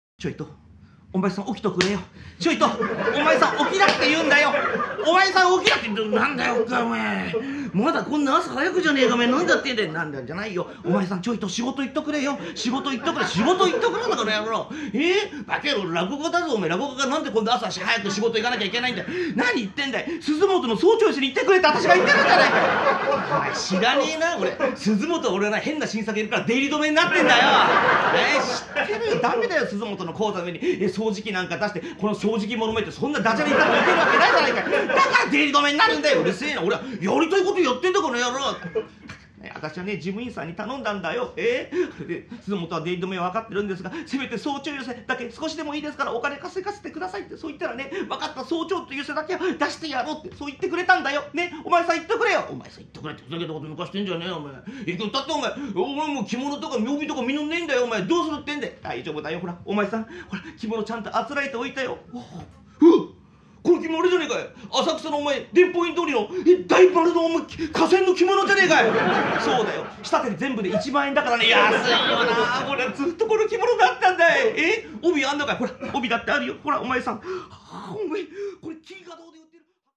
「実験落語」当時の単なる再演にとどまらず、「あの頃はああだったんだよなあ」という現在の視点がたびたび挿入されているところがポイント。
本作品は2007年8月18日に開催された「甦った！ 伝説の実験落語特集」（「無限落語」第10回、お江戸日本橋亭）で収録されたものです。